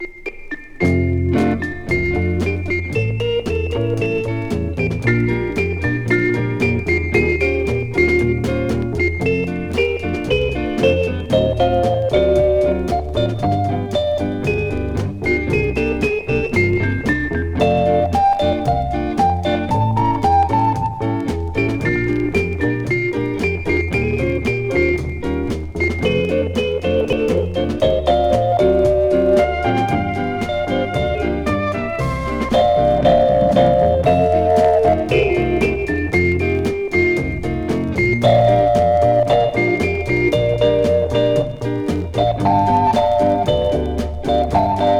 Stage & Screen, Soundtrack　USA　12inchレコード　33rpm　Stereo